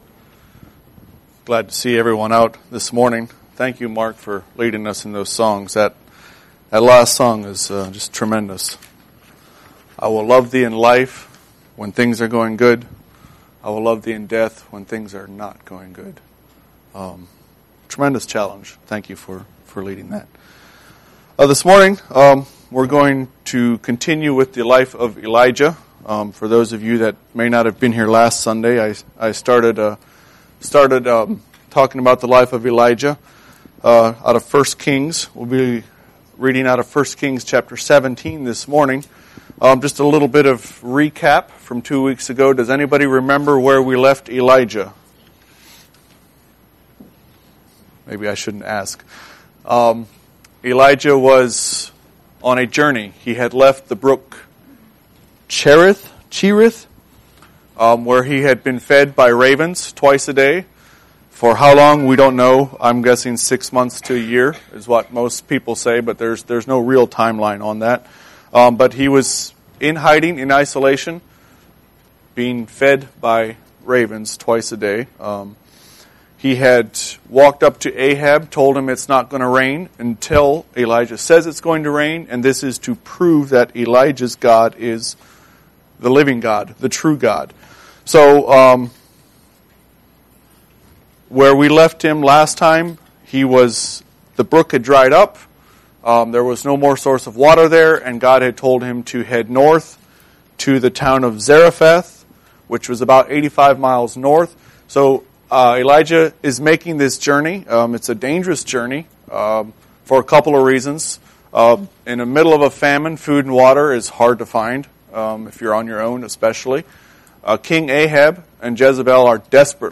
Sermons – Page 8 – Shiloh Mennonite